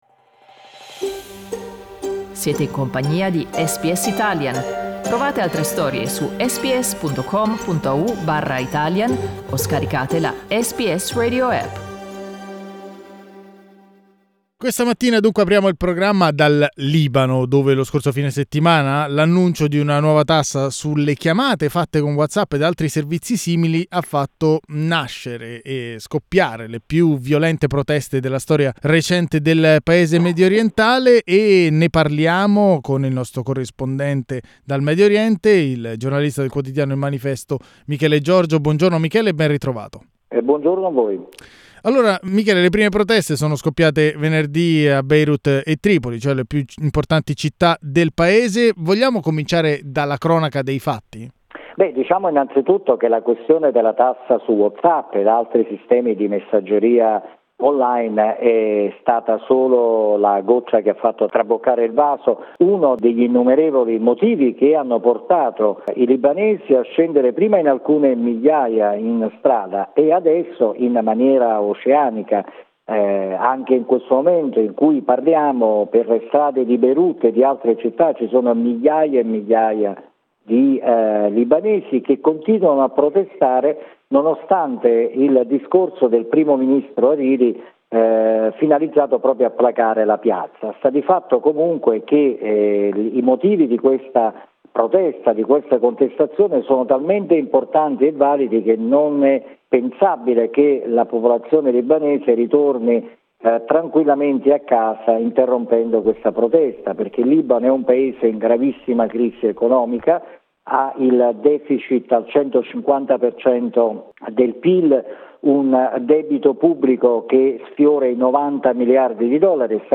Middle East correspondent